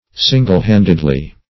\sin"gle-hand"ed*ly\